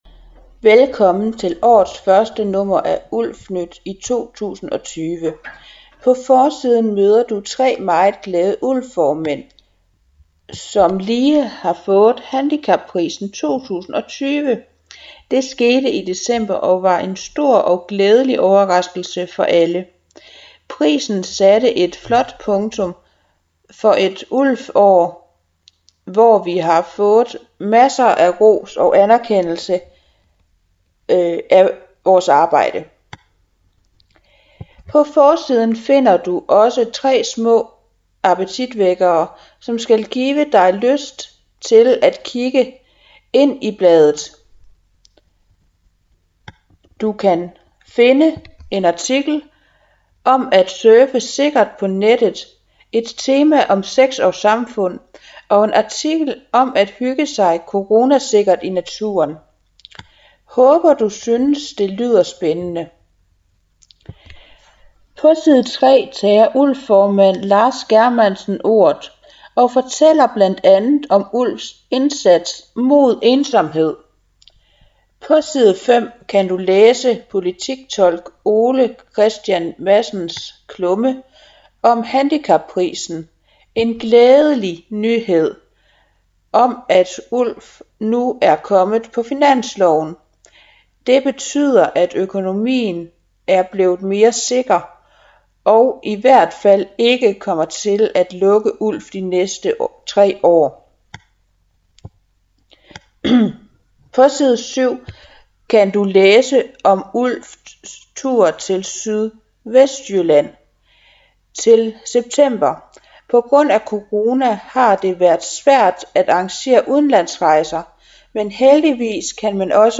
Uddrag speakes hér: